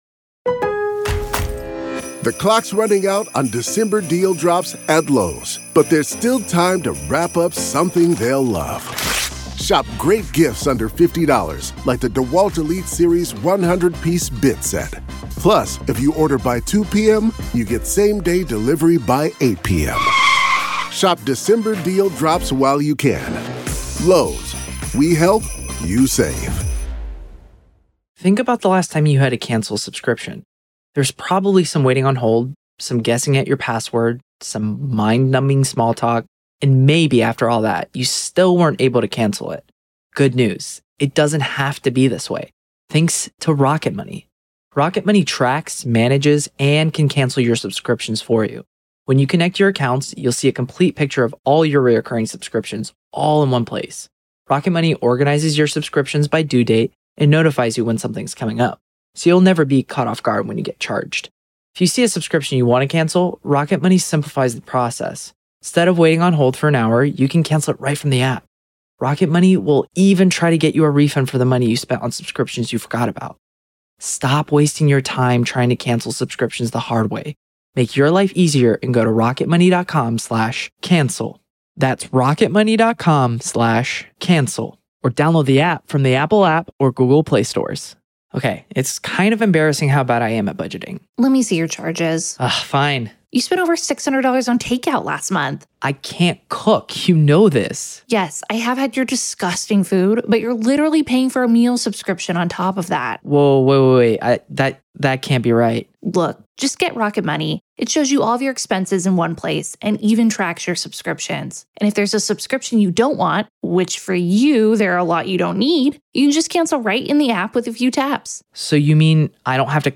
COURT AUDIO: MA v. Karen Read Murder Retrial - Motions Hearing PART 2